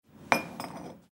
Poner una taza de té sobre un plato
Cocina
Sonidos: Acciones humanas
Sonidos: Hogar